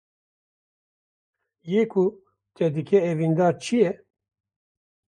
Pronounced as (IPA) /ɛviːnˈdɑːɾ/